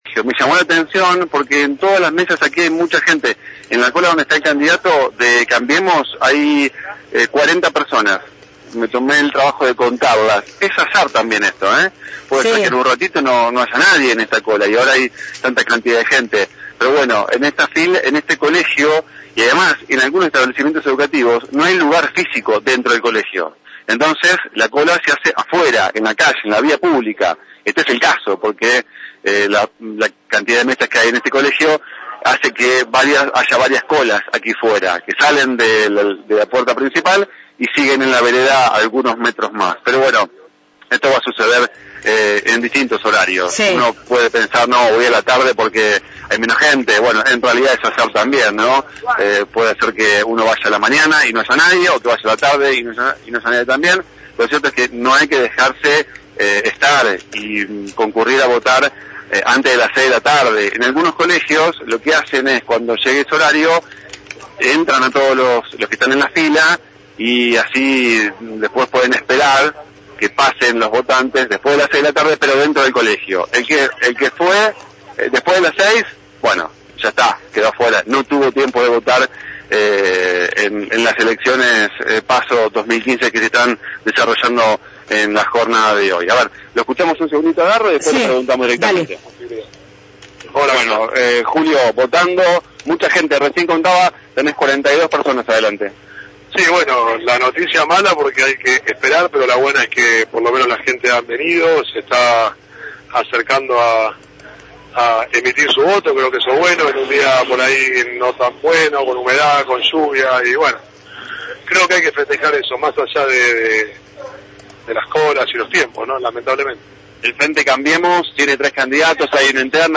con el precandidato a intendente de Cambiemos, Julio Garro.